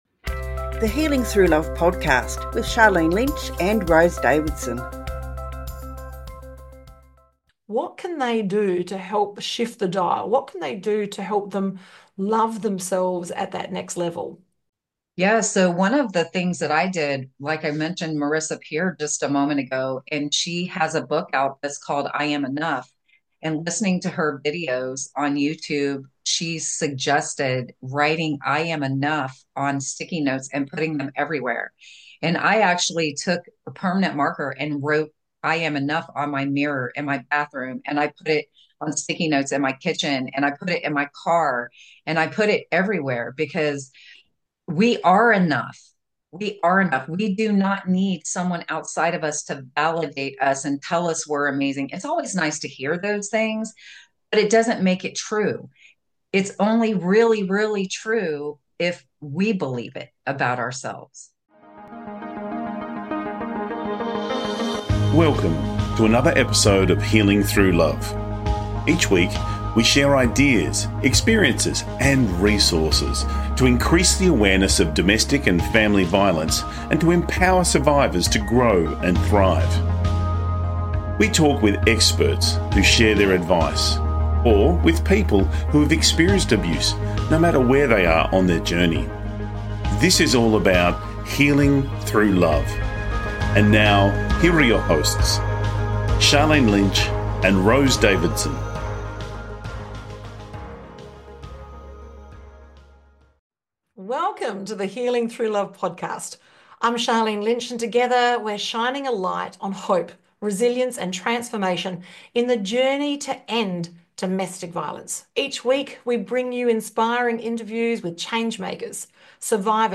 Key Points from the Interview: Self-Love as a Tool for Freedom: How valuing yourself gives you the strength to leave for go